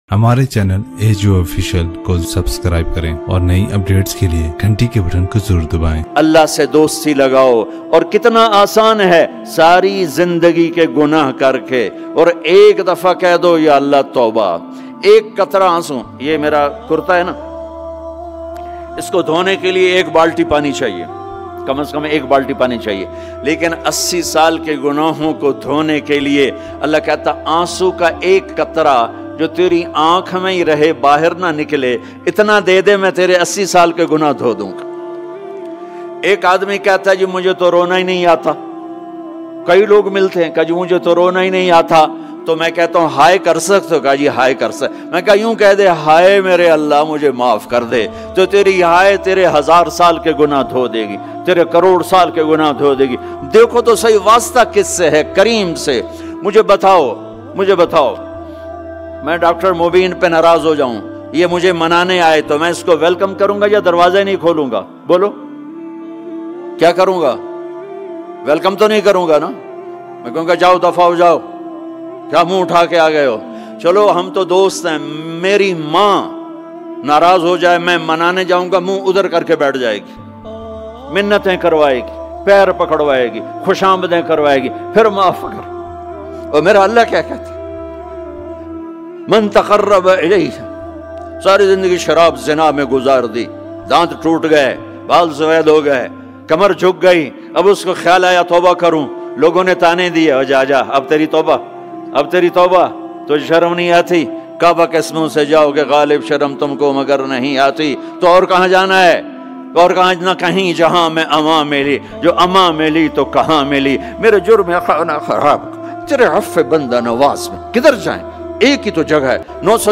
Bass Aik Aanso Ka Qatra One Tear Molana Tariq Jameel Latest Bayan MP3